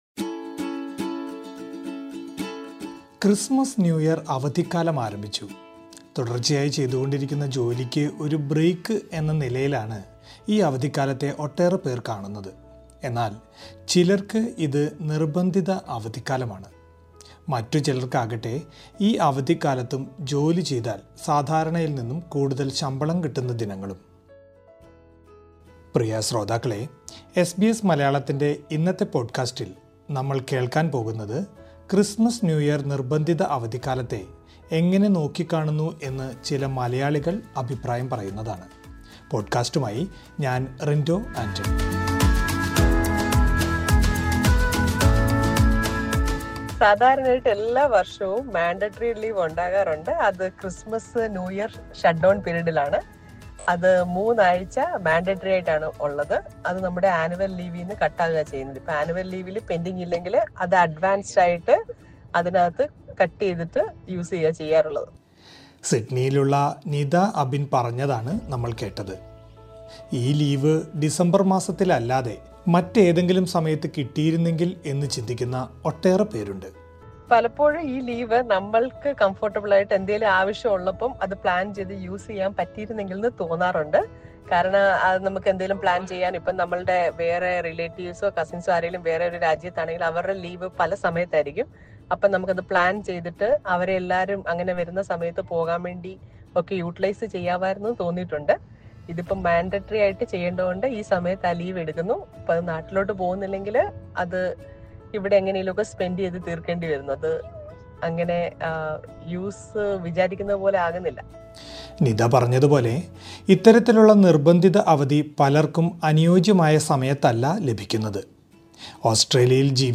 ക്രിസ്മസ്-ന്യൂ ഇയർ കാലത്ത് ഒട്ടുമിക്ക കമ്പനികളും ജീവനക്കാർക്ക് നിർബന്ധിത അവധി നൽകാറുണ്ട്. ഇത്തരം അവധികളെ നേട്ടമായി കാണുന്നവരും, നിർബന്ധിത അവധികൾ അനാവശ്യമാണെന്ന അഭിപ്രായമുള്ളവരും നമുക്കിടയിലുണ്ട്. ഓസ്‌ട്രേലിയയിലെ നിർബന്ധിത അവധിക്കാലത്തെ കുറിച്ച് ചില മലയാളികൾ അഭിപ്രായങ്ങൾ പങ്കുവെയ്ക്കുന്നത് കേൾക്കാം, മുകളിലെ പ്ലെയറിൽ നിന്നും...